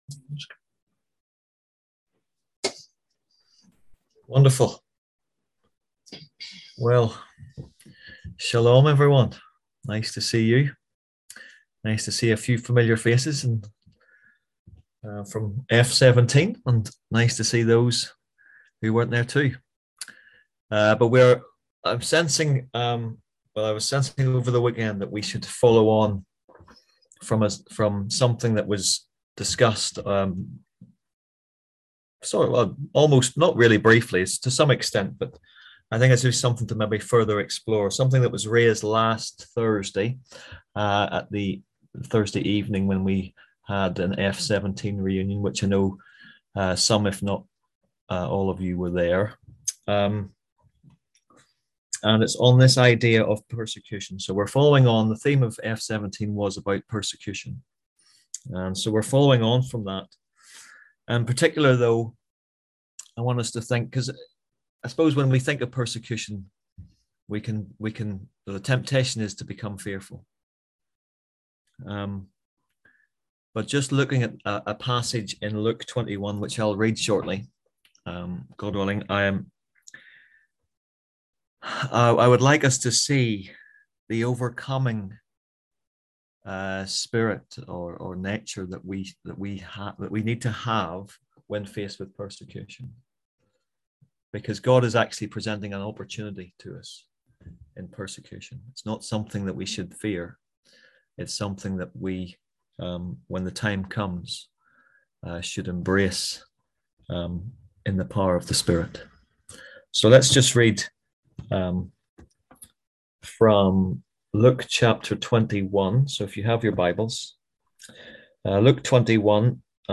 On August 1st at 7pm – 8:30pm on ZOOM ASK A QUESTION – Our lively discussion forum. Tonight’s topic: Equipped for battle – learning the art of warfare – [...]